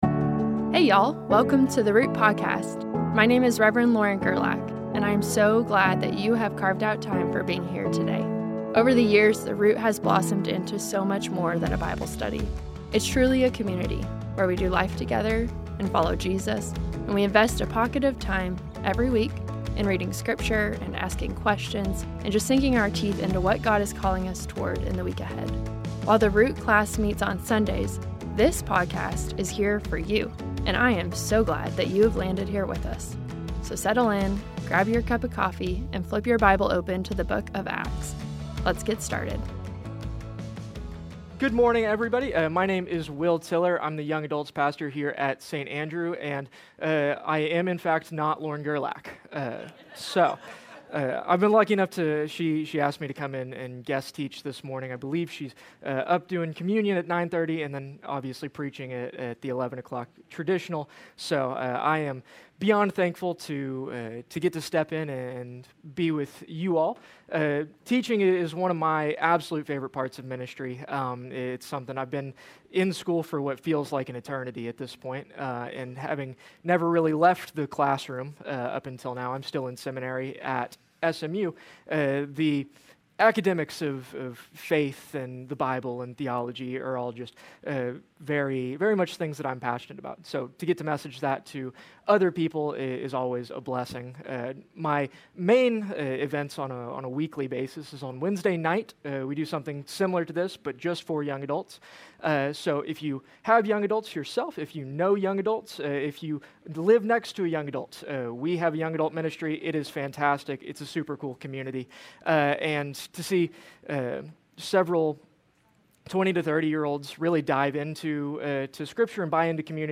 A message from the series "Acts."